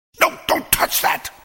No don't touch that! - Meme Sound Effect